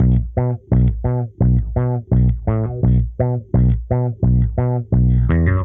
Index of /musicradar/dusty-funk-samples/Bass/85bpm